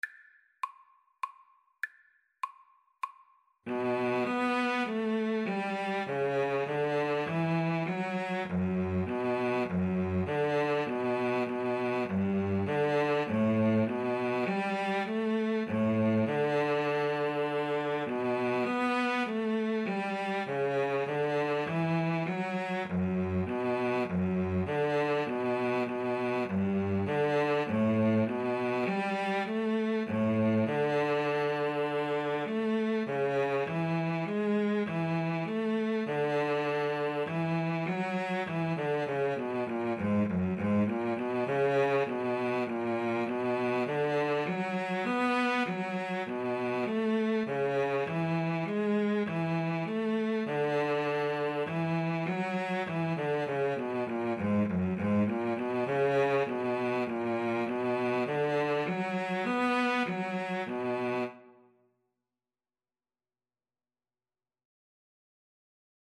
3/4 (View more 3/4 Music)
Classical (View more Classical Cello Duet Music)